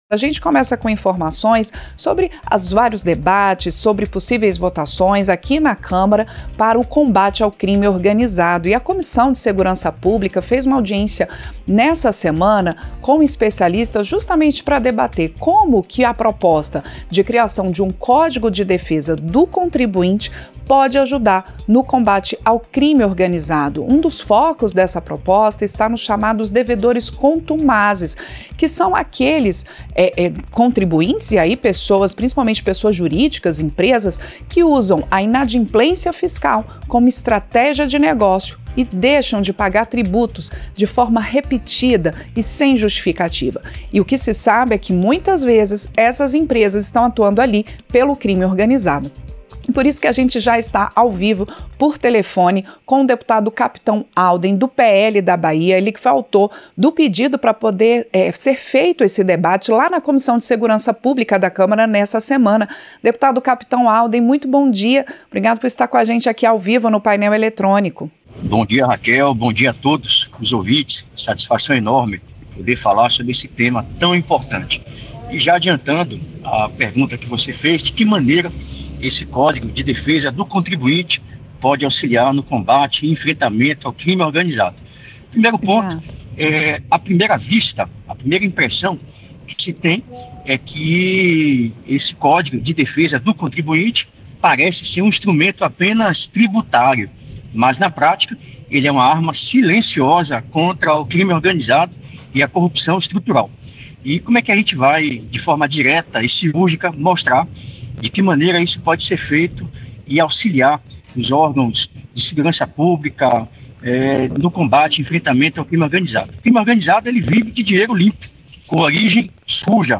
Entrevista - Dep. Capitão Alden (PL-BA)